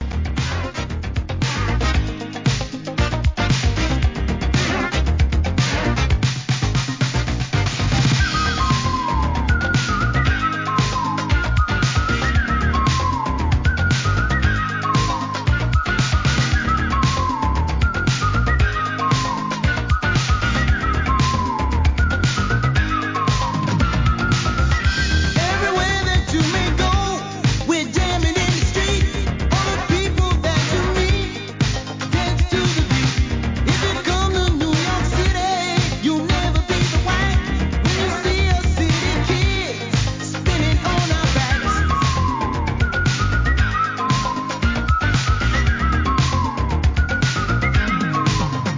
HIP HOP/R&B
パーカッシヴなエレクトロ・ファンク・トラックのOLD SCHOOL! VOCAL物です!